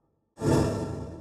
Trimmed-Holy Buffs
sfx updates